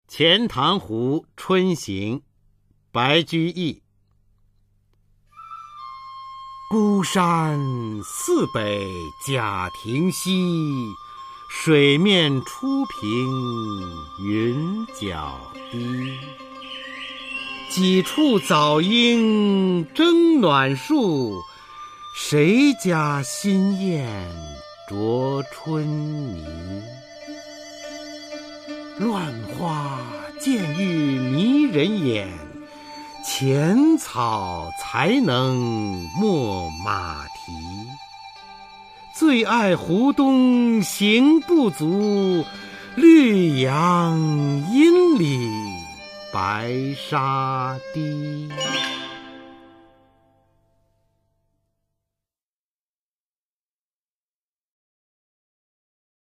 [隋唐诗词诵读]白居易-钱塘湖春行 唐诗诵读